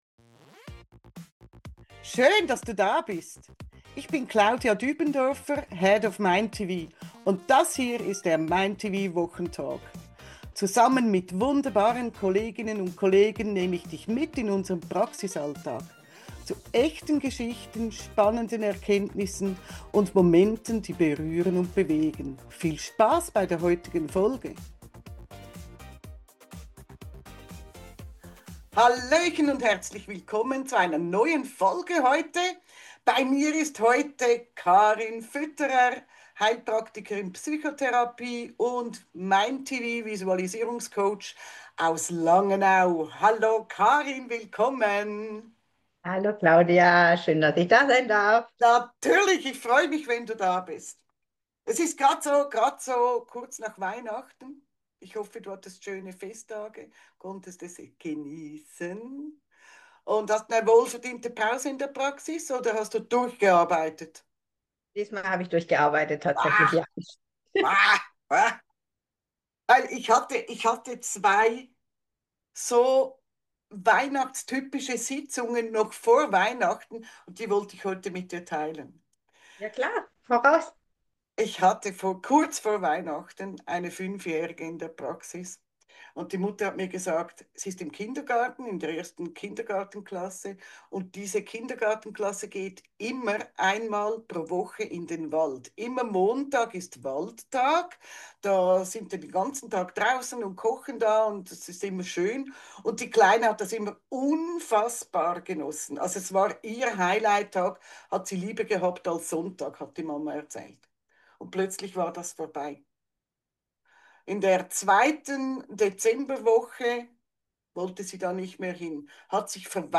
Der spontane Wochentalk